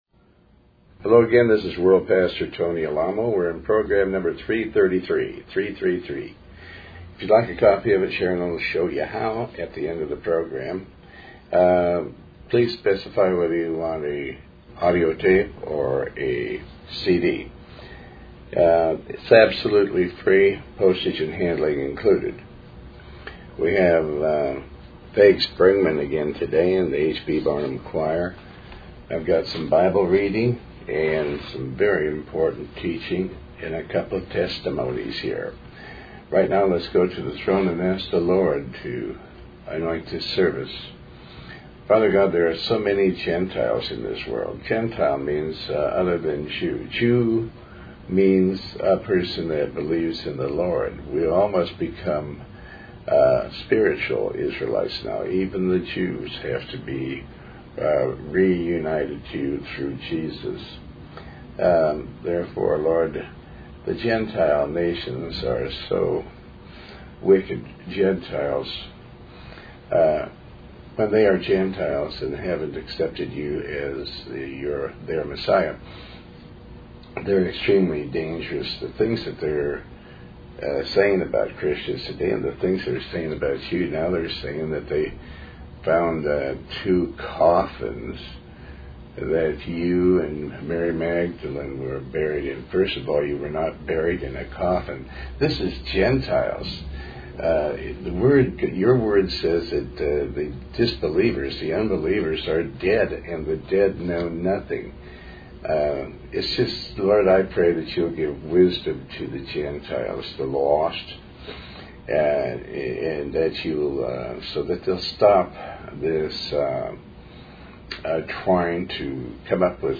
Talk Show Episode, Audio Podcast, Tony Alamo and Program333 on , show guests , about Tony Alamo with Tony Alamo World Wide Ministries, categorized as Health & Lifestyle,History,Love & Relationships,Philosophy,Psychology,Christianity,Inspirational,Motivational,Society and Culture